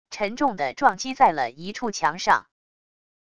沉重的撞击在了一处墙上wav音频